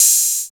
28 OP HAT.wav